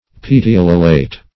Petiolulate \Pet`i*ol"u*late\, a.